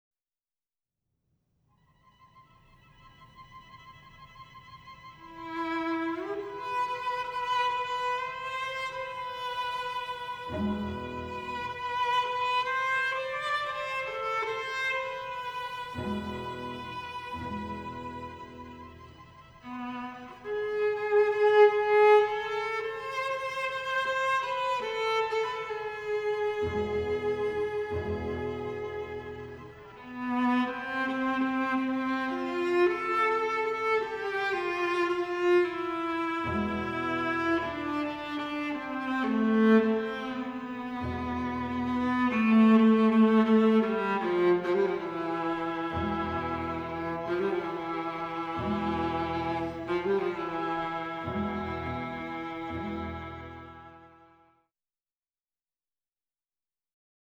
a dreamscape of three Dances for String Quartet.